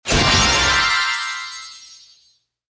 jackpot_select_luxury.mp3